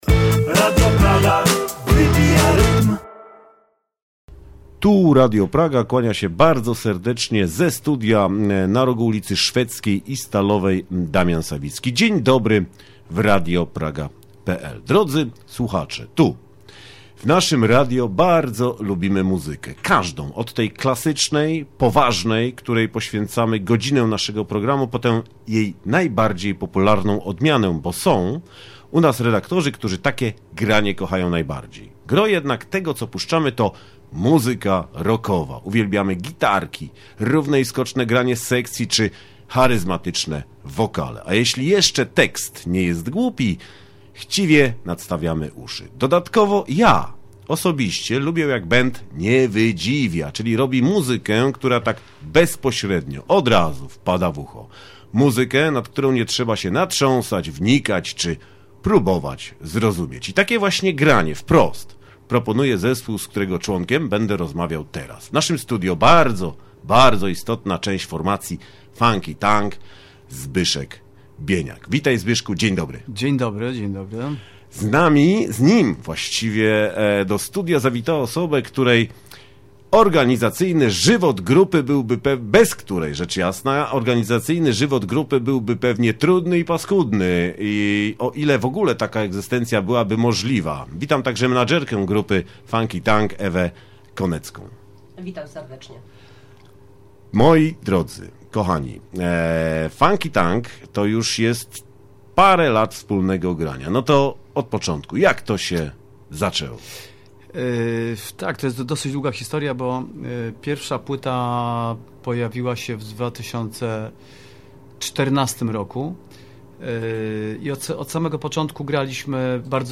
Posłuchajcie naszej rozmowy na temat samej grupy, ostatniej płyty i innych fidrygałek. Sporo tu także muzyki.